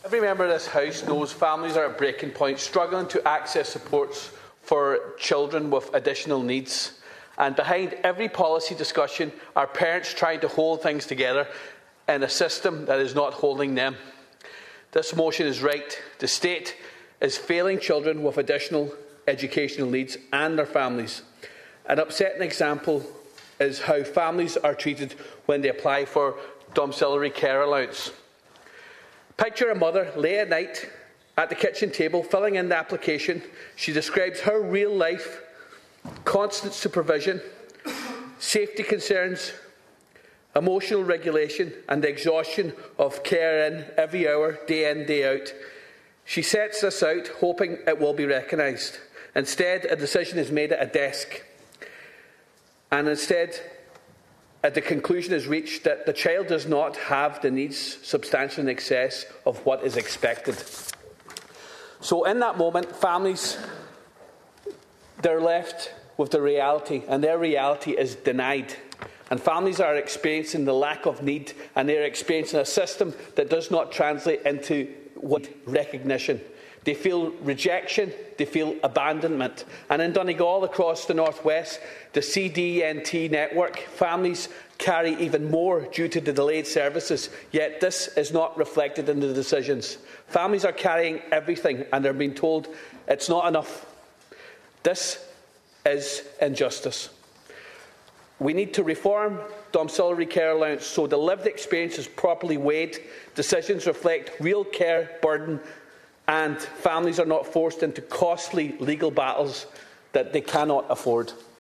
Deputy Ward also says services in Donegal, including the Children’s Disability Network Team, are not meeting the needs of the county: